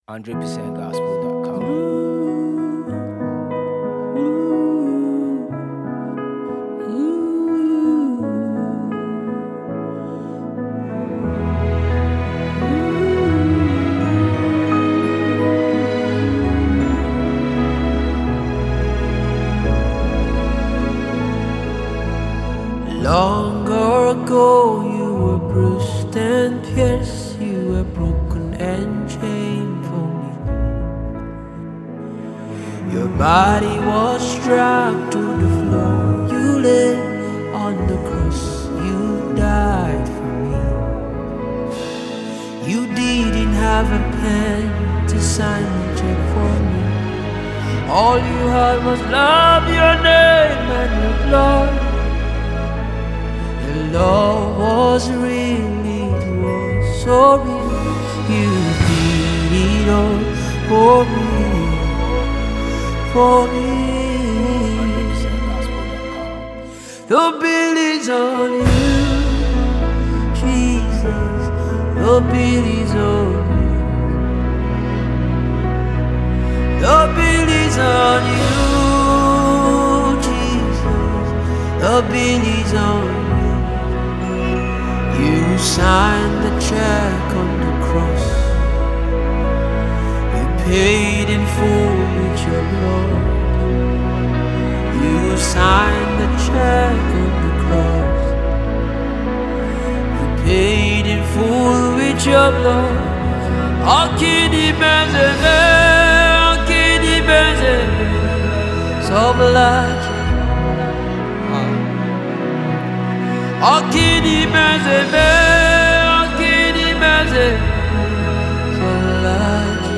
Nigerian Renowned Gospel artiste